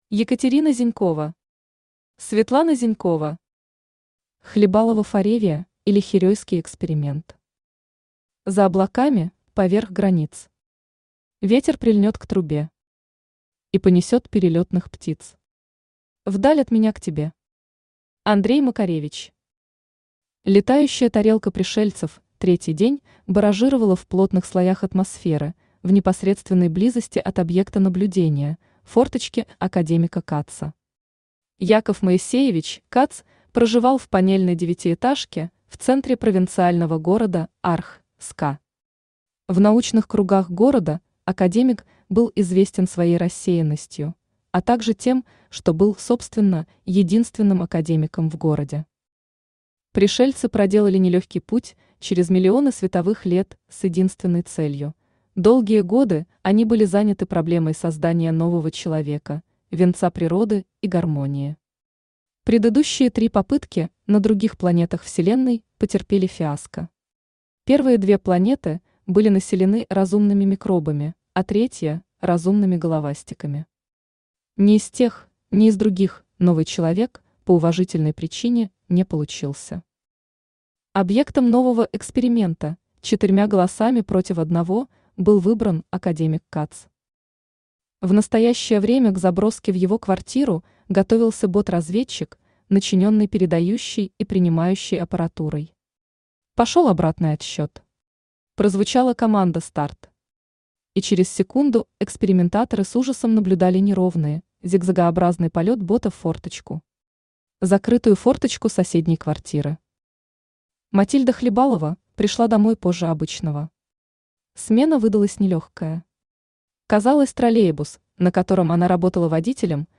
Аудиокнига Хлебалова фореве, или Херейский эксперимент | Библиотека аудиокниг
Aудиокнига Хлебалова фореве, или Херейский эксперимент Автор Екатерина Владимировна Зинькова Читает аудиокнигу Авточтец ЛитРес.